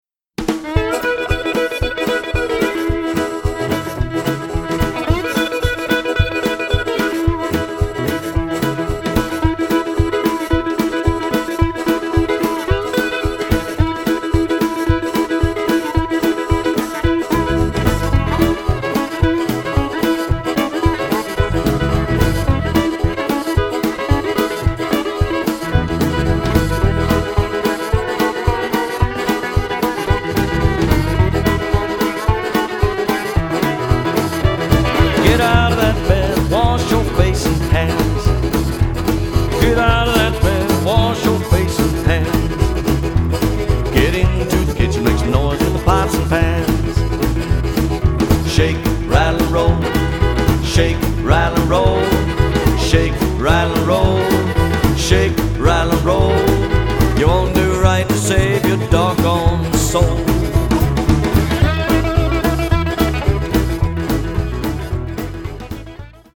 The sound of the disc is warm and acoustic